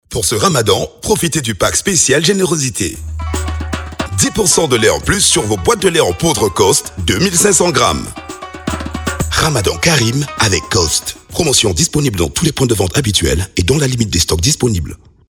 spot-radio-promo-djibouti-2_157_5.mp3